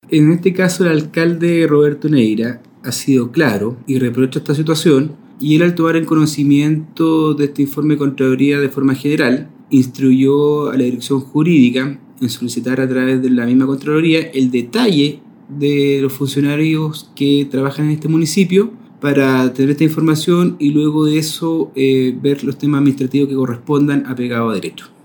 Una vez recibido el informe detallado, se analizarán las medidas a tomar, las cuales podrían incluir sumarios administrativos u otras acciones conforme a la normativa. Así lo señaló el administrador municipal y alcalde (s) de Temuco, Ricardo Toro.